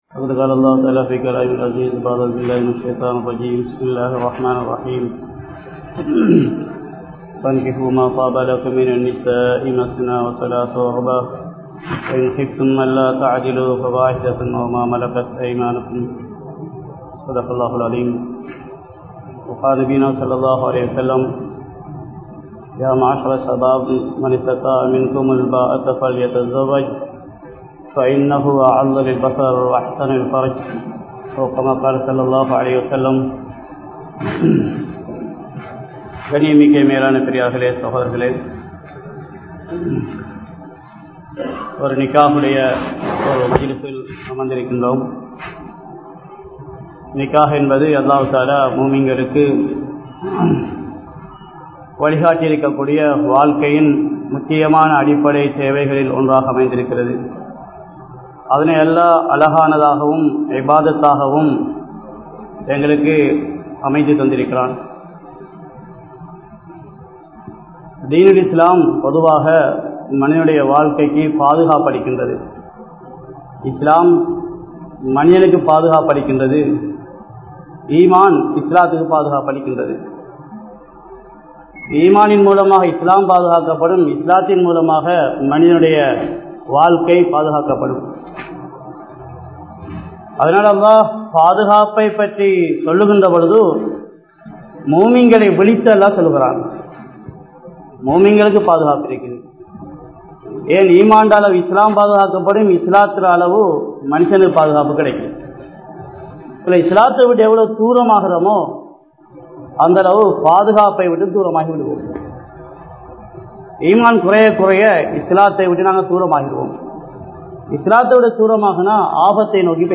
Maanaththin Perumathi (மானத்தின் பெறுமதி) | Audio Bayans | All Ceylon Muslim Youth Community | Addalaichenai